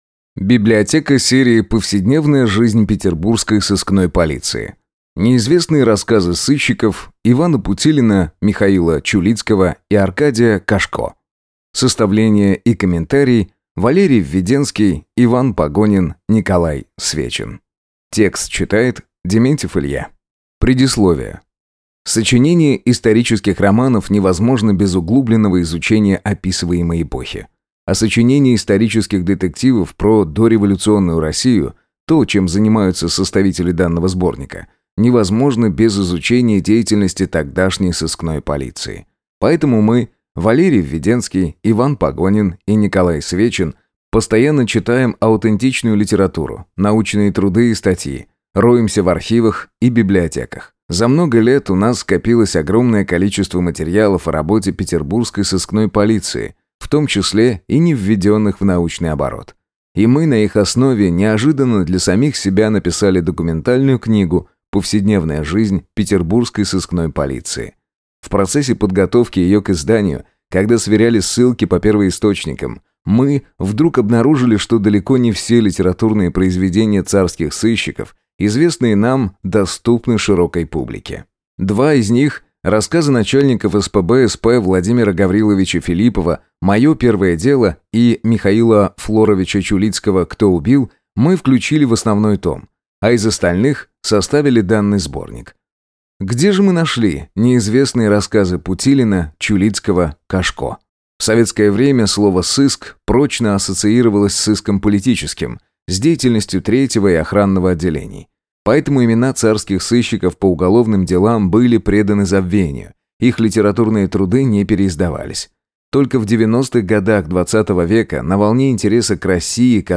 Аудиокнига Неизвестные рассказы сыщиков Ивана Путилина, Михаила Чулицкого и Аркадия Кошко | Библиотека аудиокниг